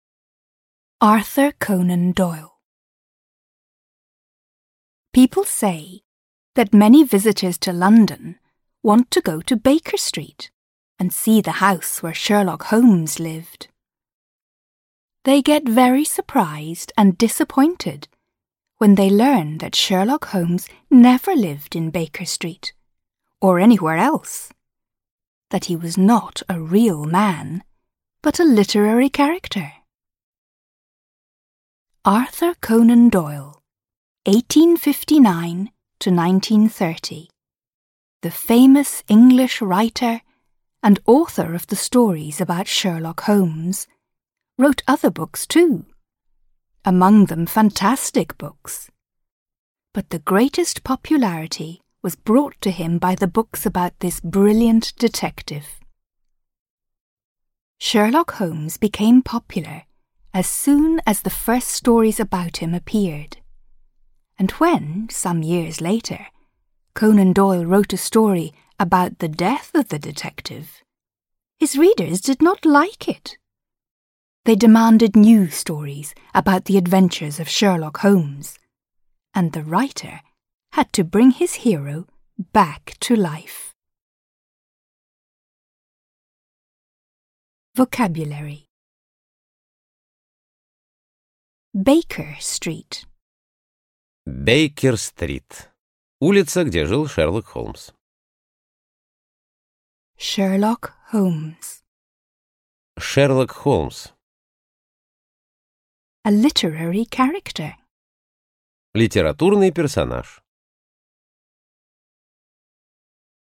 Аудиокнига Sherlock Holmes: The Blue Carbuncle. The Problem of Thor Bridge | Библиотека аудиокниг